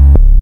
wrong.ogg